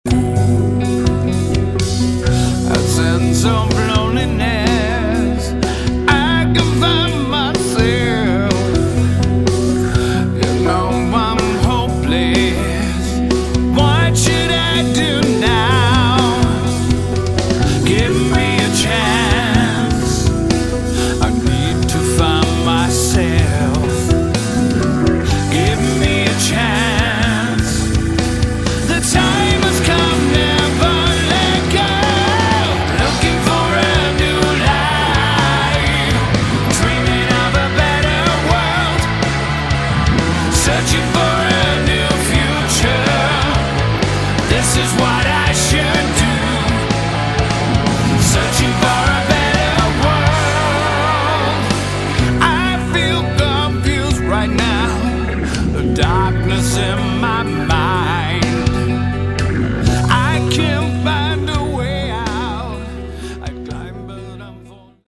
Category: Hard Rock
vocals
bass
guitars
drums